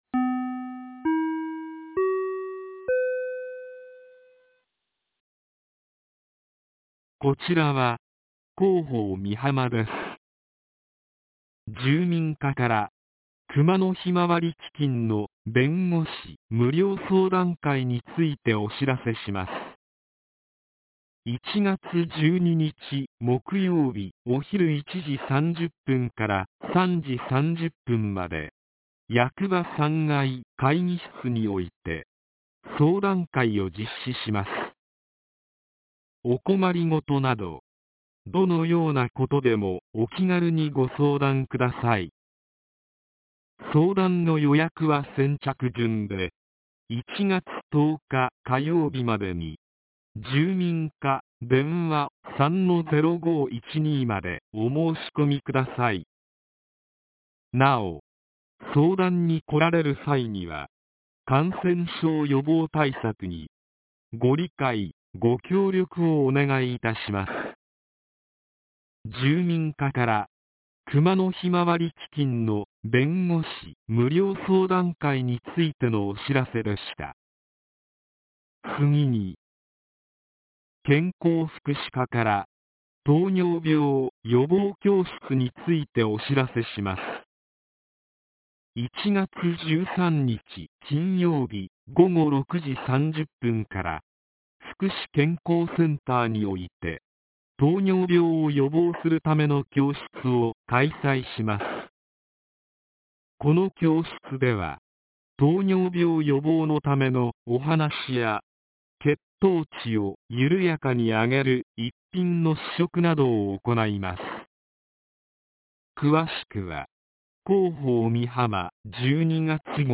放送音声
■防災行政無線情報■